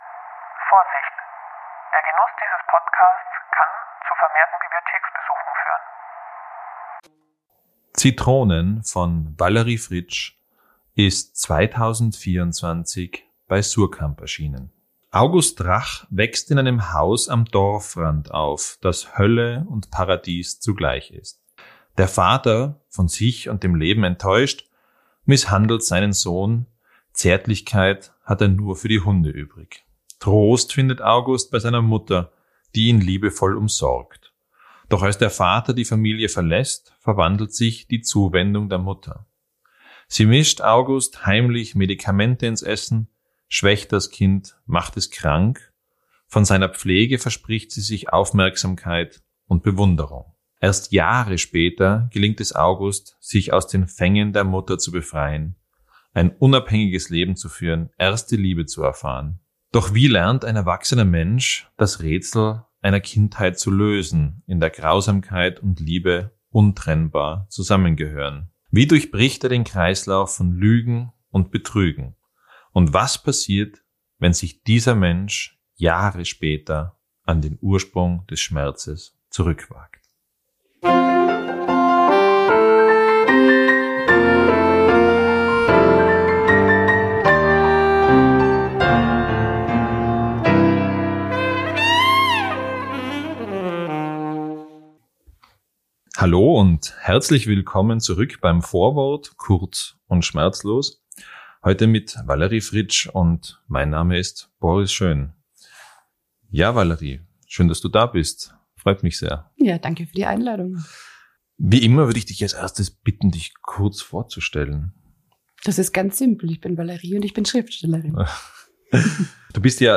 Innsbruck liest-Special: im Gespräch mit Autorin Valerie Fritsch ("Zitronen") ~ S'Vorwort Podcast